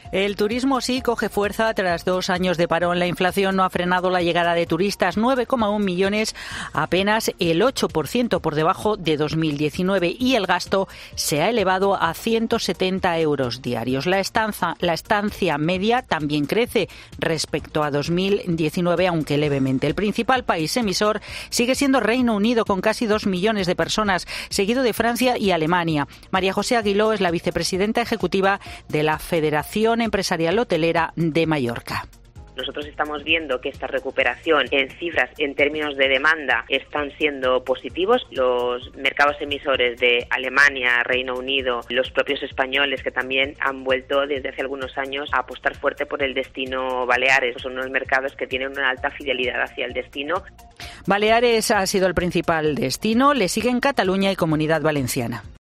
Te da más detalles la redactora de Economía